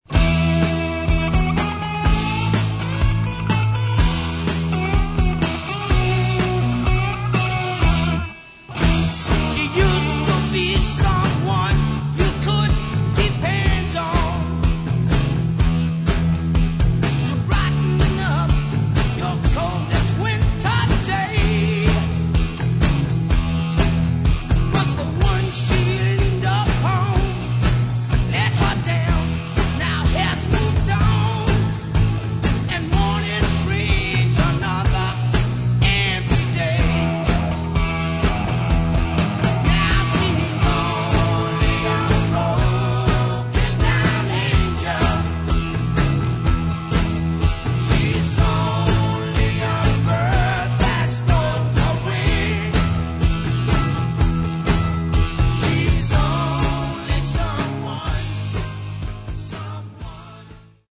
Рок
Великолепны все партии – вокал, гитары, барабаны.